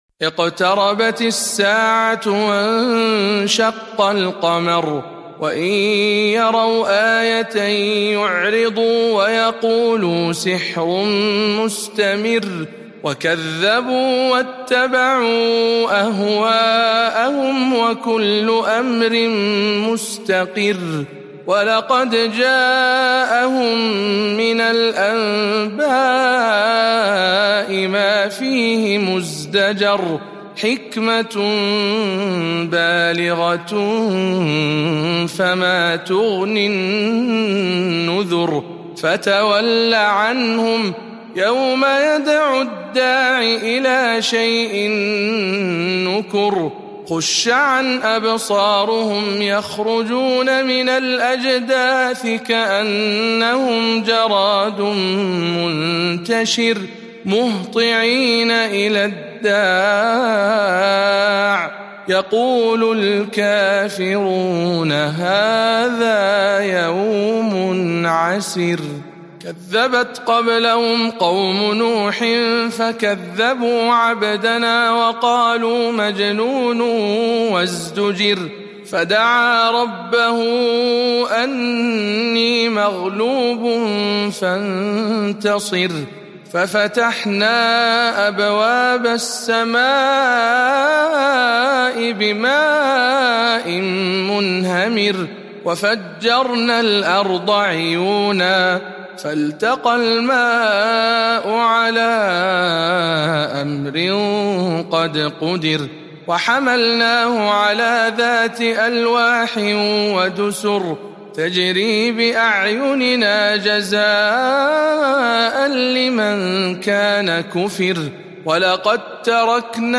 سورة القمر برواية شعبة عن عاصم